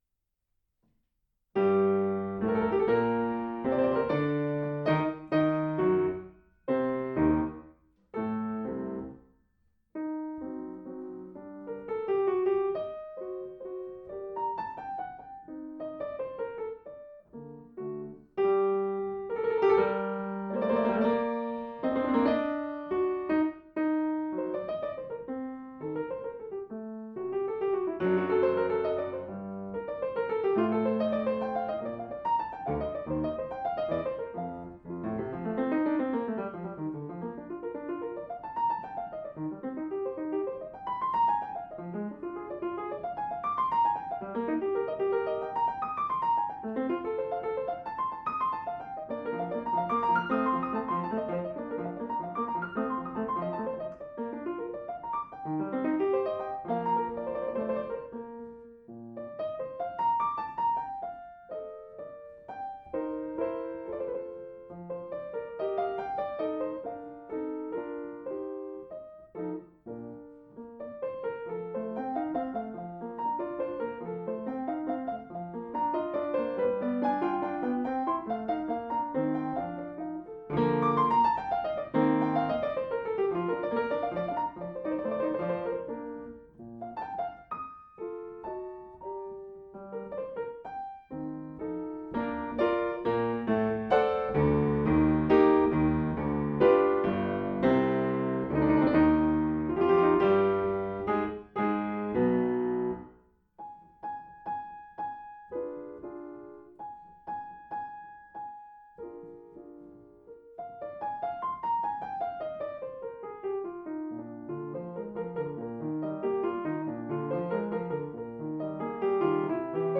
La obra para piano solo de Mozart, conocida generalmente por sus 18 sonatas, 16 ciclos de variaciones y 4 Fantasías, consta además de un importante cuerpo de piezas sueltas compuestas a lo largo de su corta vida.
Primer movimiento: Allegro